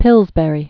(pĭlzbĕrē, -bə-rē), Charles Alfred 1842-1899.